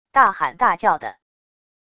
大喊大叫的wav音频